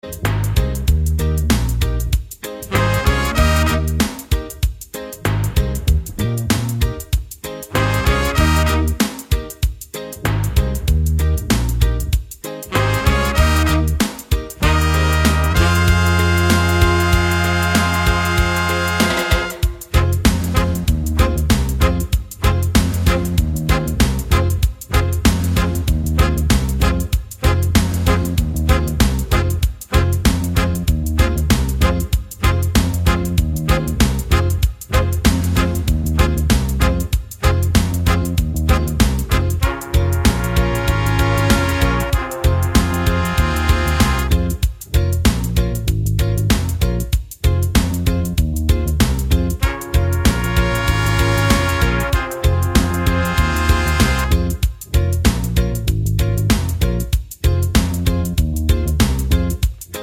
no Backing Vocals Reggae 4:02 Buy £1.50